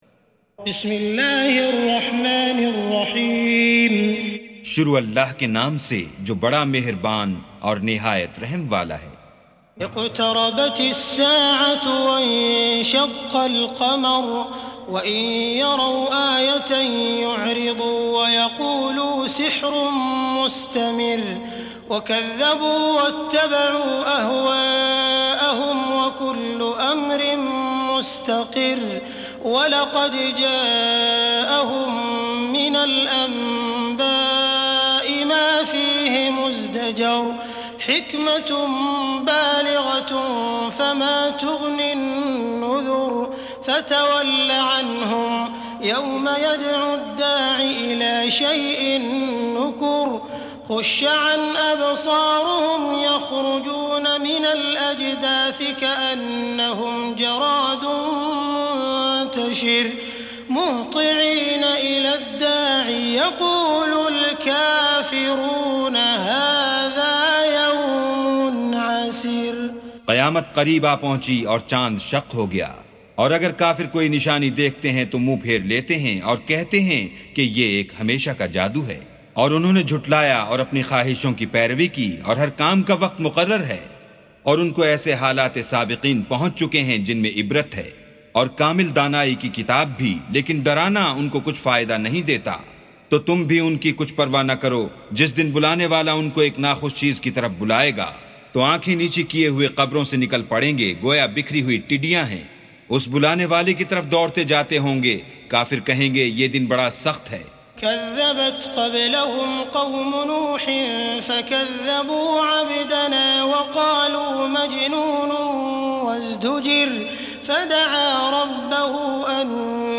سُورَةُ القَمَرِ بصوت الشيخ السديس والشريم مترجم إلى الاردو